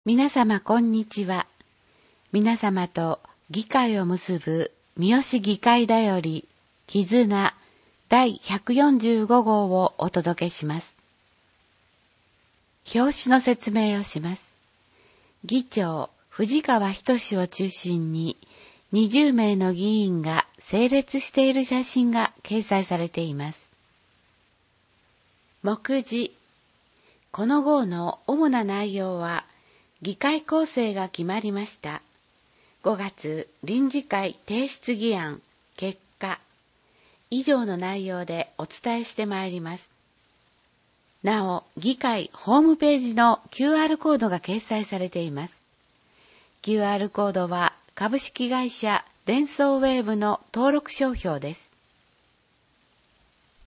『声の議会広報』は、「みよし議会だより きずな」を音声情報にしたもので、平成29年6月15日発行の第110号からボランティア団体「やまびのこ会」の協力によりサービス提供をはじめました。（一部AI自動音声（テキスト読み上げ）ソフト「VOICEVOX Nemo」を使用）